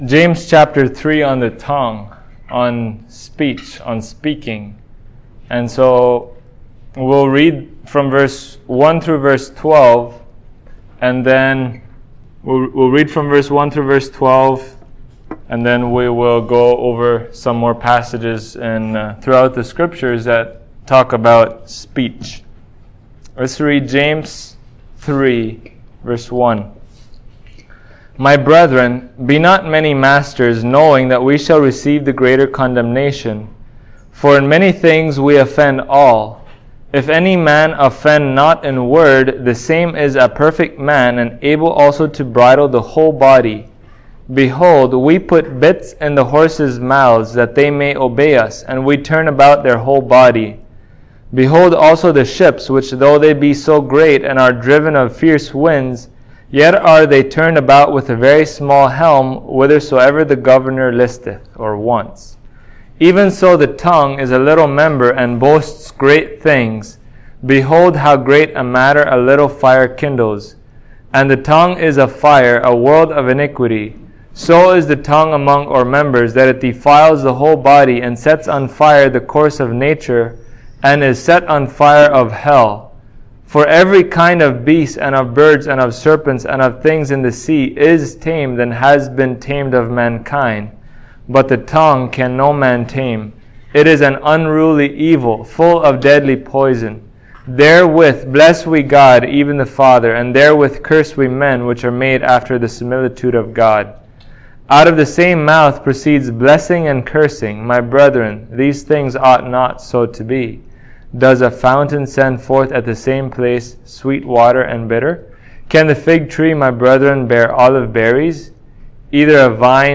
James 3:1-12 Service Type: Sunday Morning Believers are not saved by works